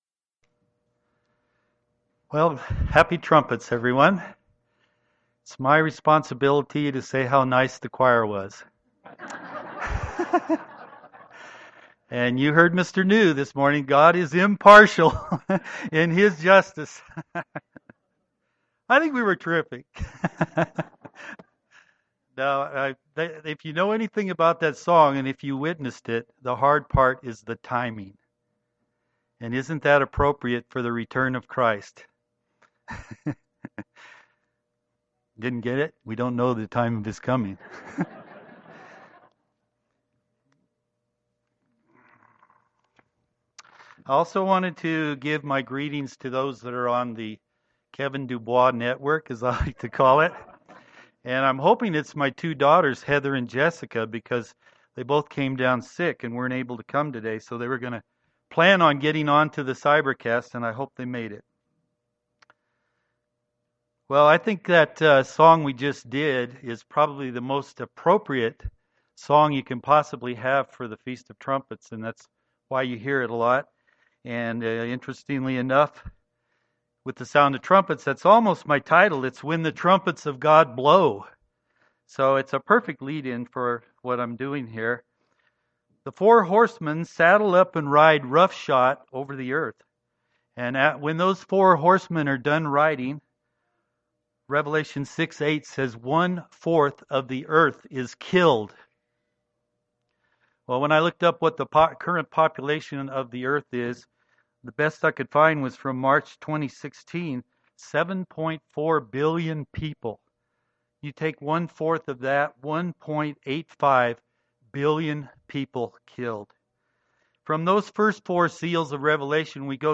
Given in Denver, CO